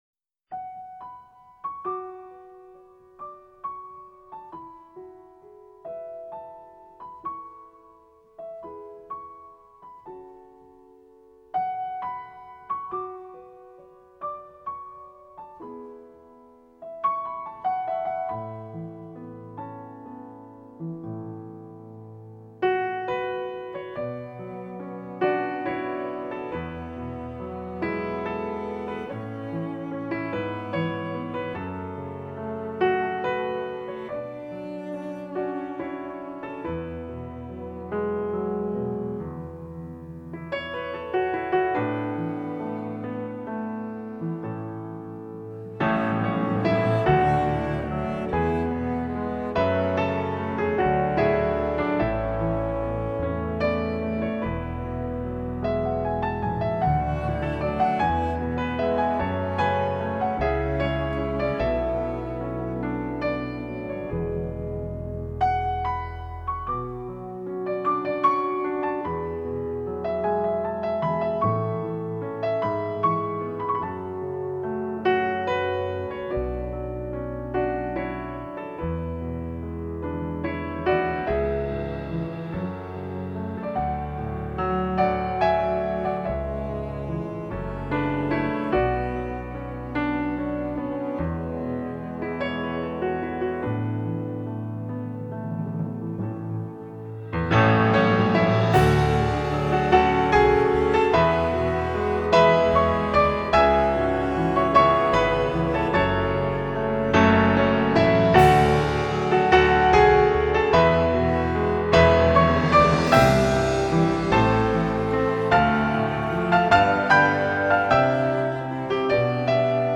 类型:NewAge
流派:Instrumental